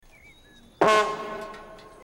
Etiquetas: meme, soundboard